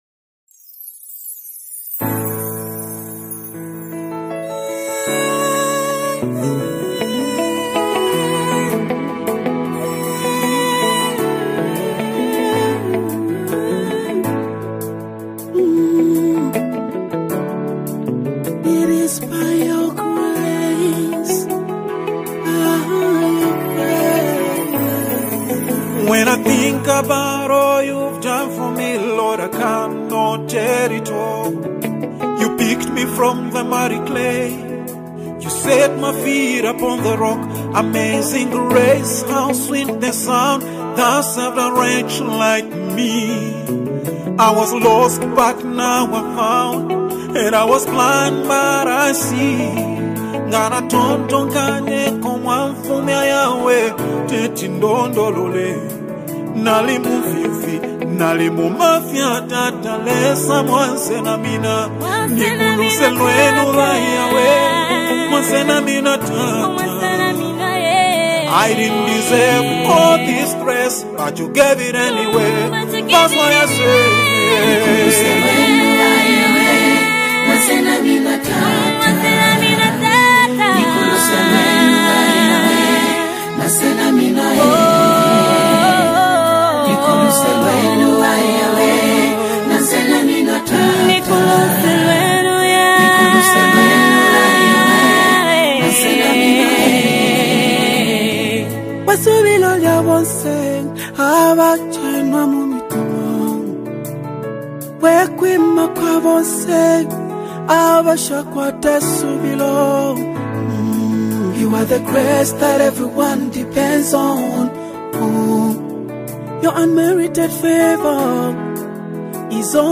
a moving GOSPEL ANTHEM
ANOINTED ZAMBIAN GOSPEL MUSIC